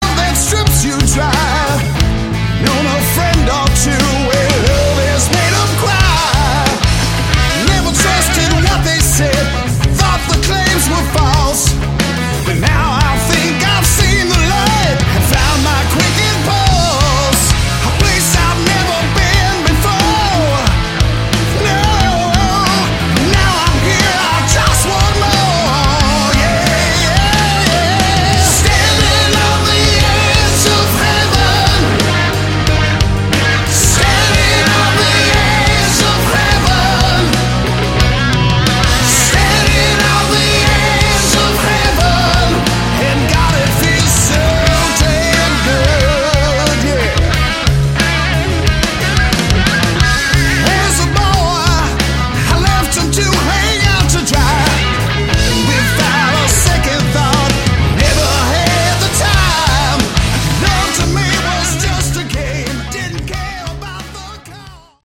Category: Hard Rock
Vocals
Drums
Guitar